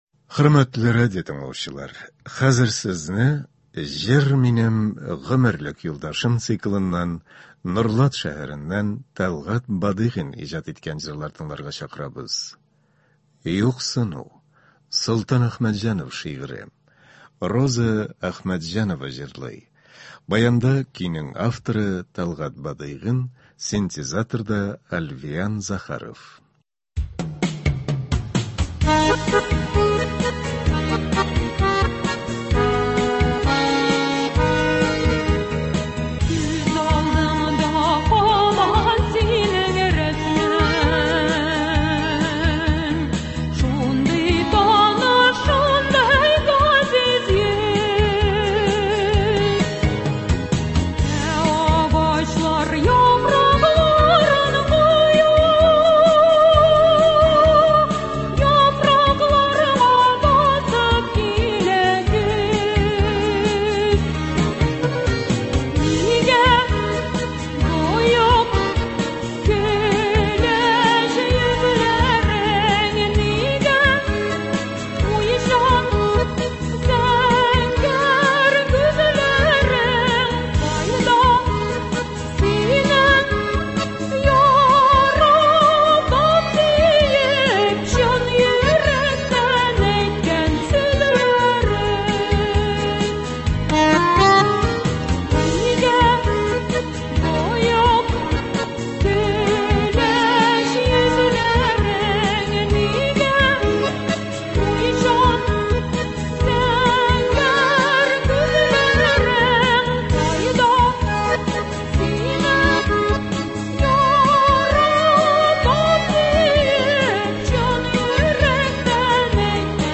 Үзешчән башкаручылар чыгышы.
Концерт (22.01.24)